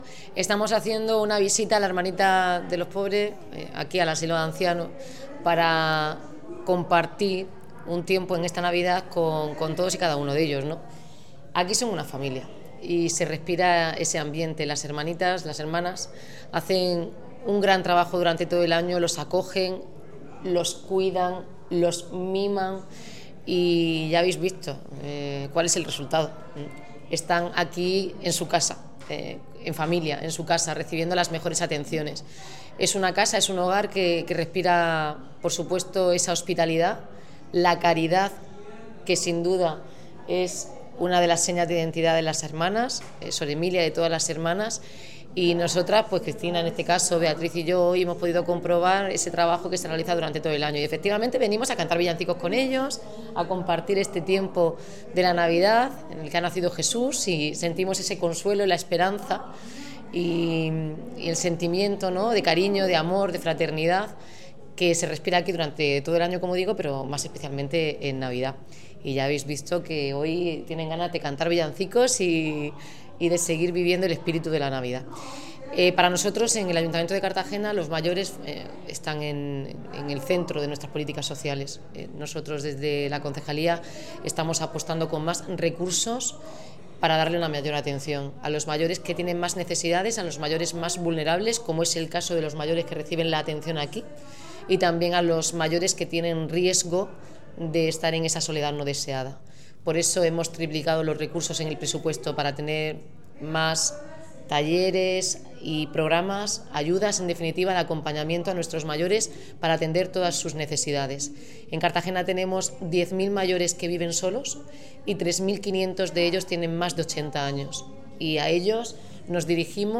La alcaldesa visita el asilo de las Hermanitas de los Pobres para compartir la Navidad con los residentes y reafirmar el compromiso social del Gobierno local